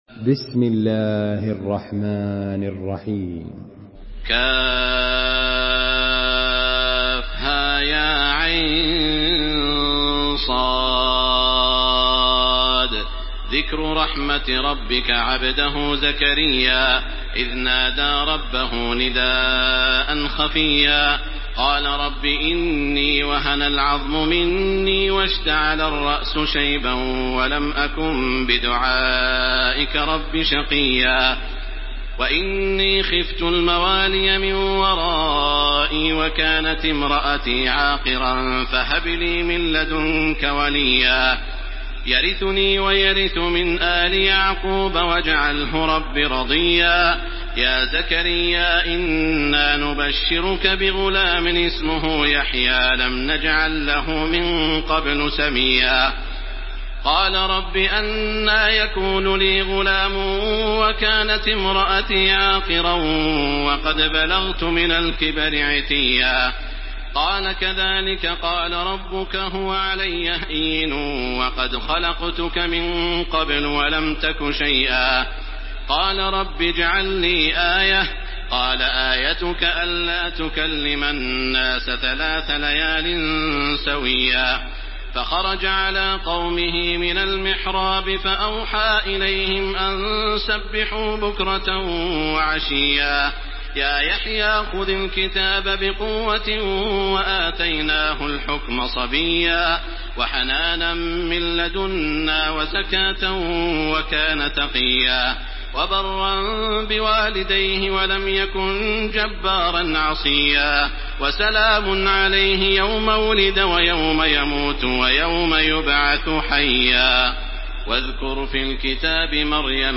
Surah مريم MP3 by تراويح الحرم المكي 1429 in حفص عن عاصم narration.
مرتل حفص عن عاصم